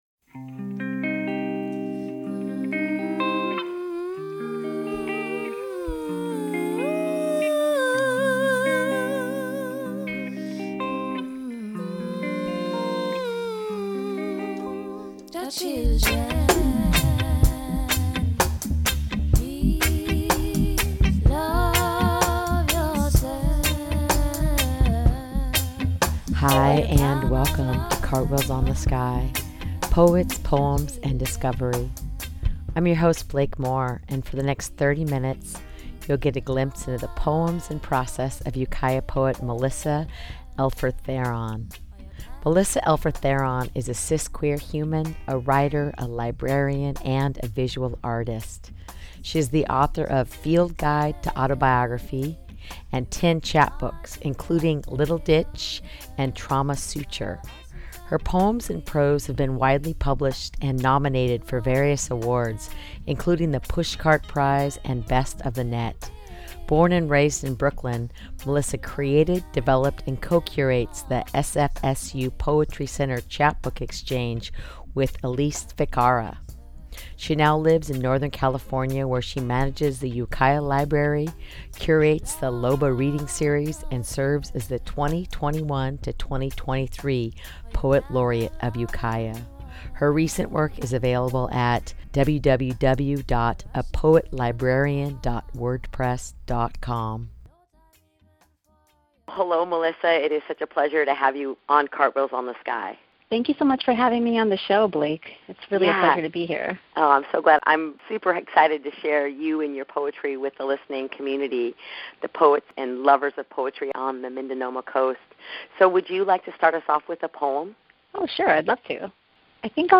Highlighting a new poet in conversation each week, the show offers fresh, intelligent, living poetry and lively discussions.